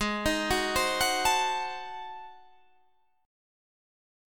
Listen to G#7b9 strummed